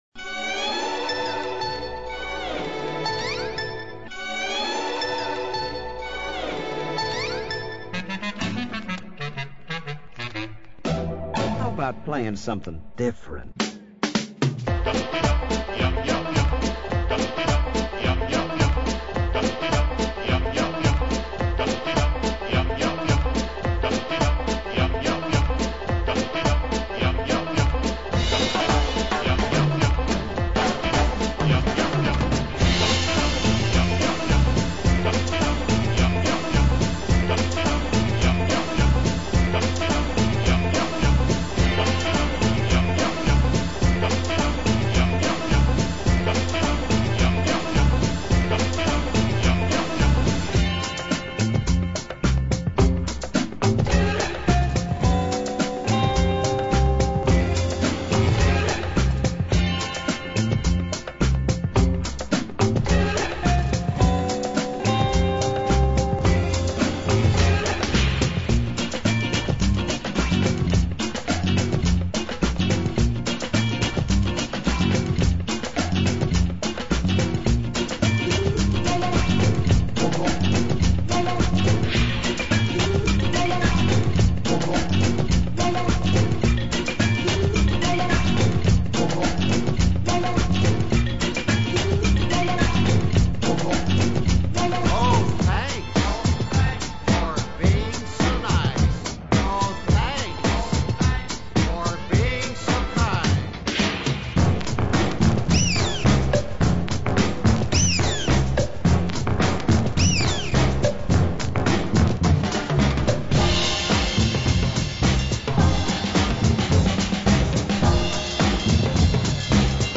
[hip lounge]   Comfort Cake